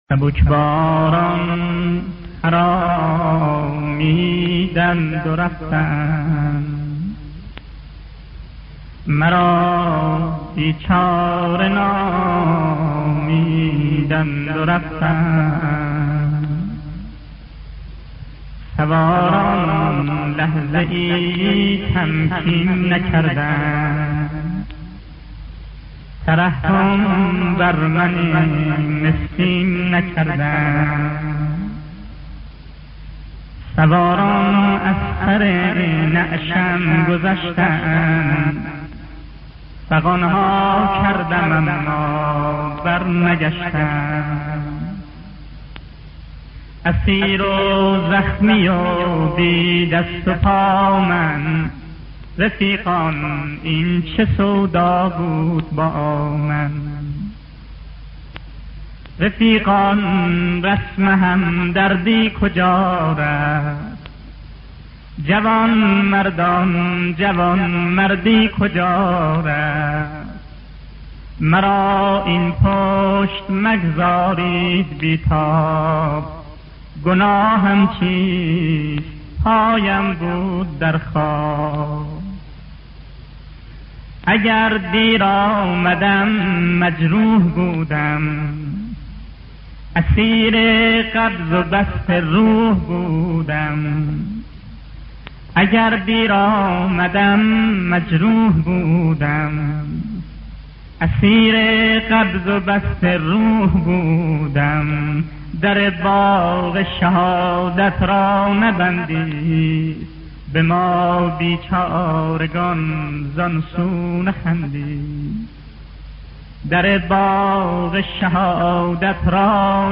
نوحه سبکبالان خرامیدند و رفتند با صدای صادق آهنگران را بشنوید.